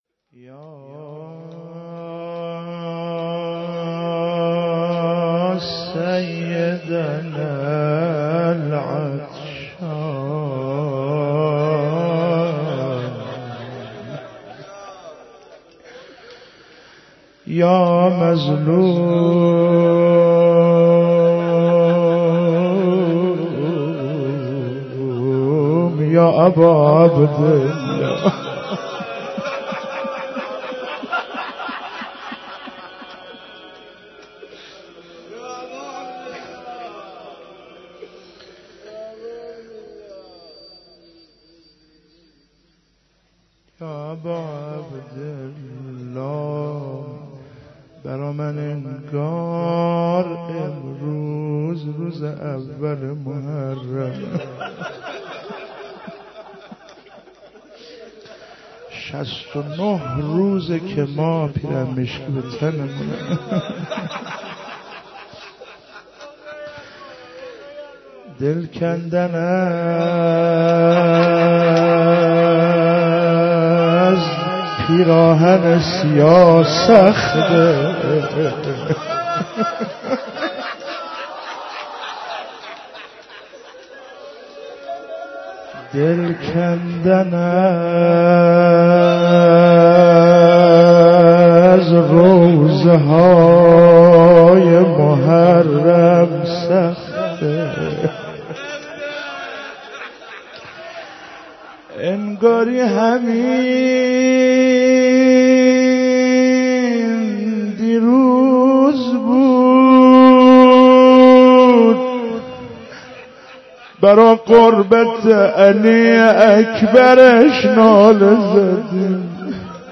شهادت امام حسن عسکری علیه السلام 95_روضه_مجلس ختم گرفتم حسنم ریخت به هم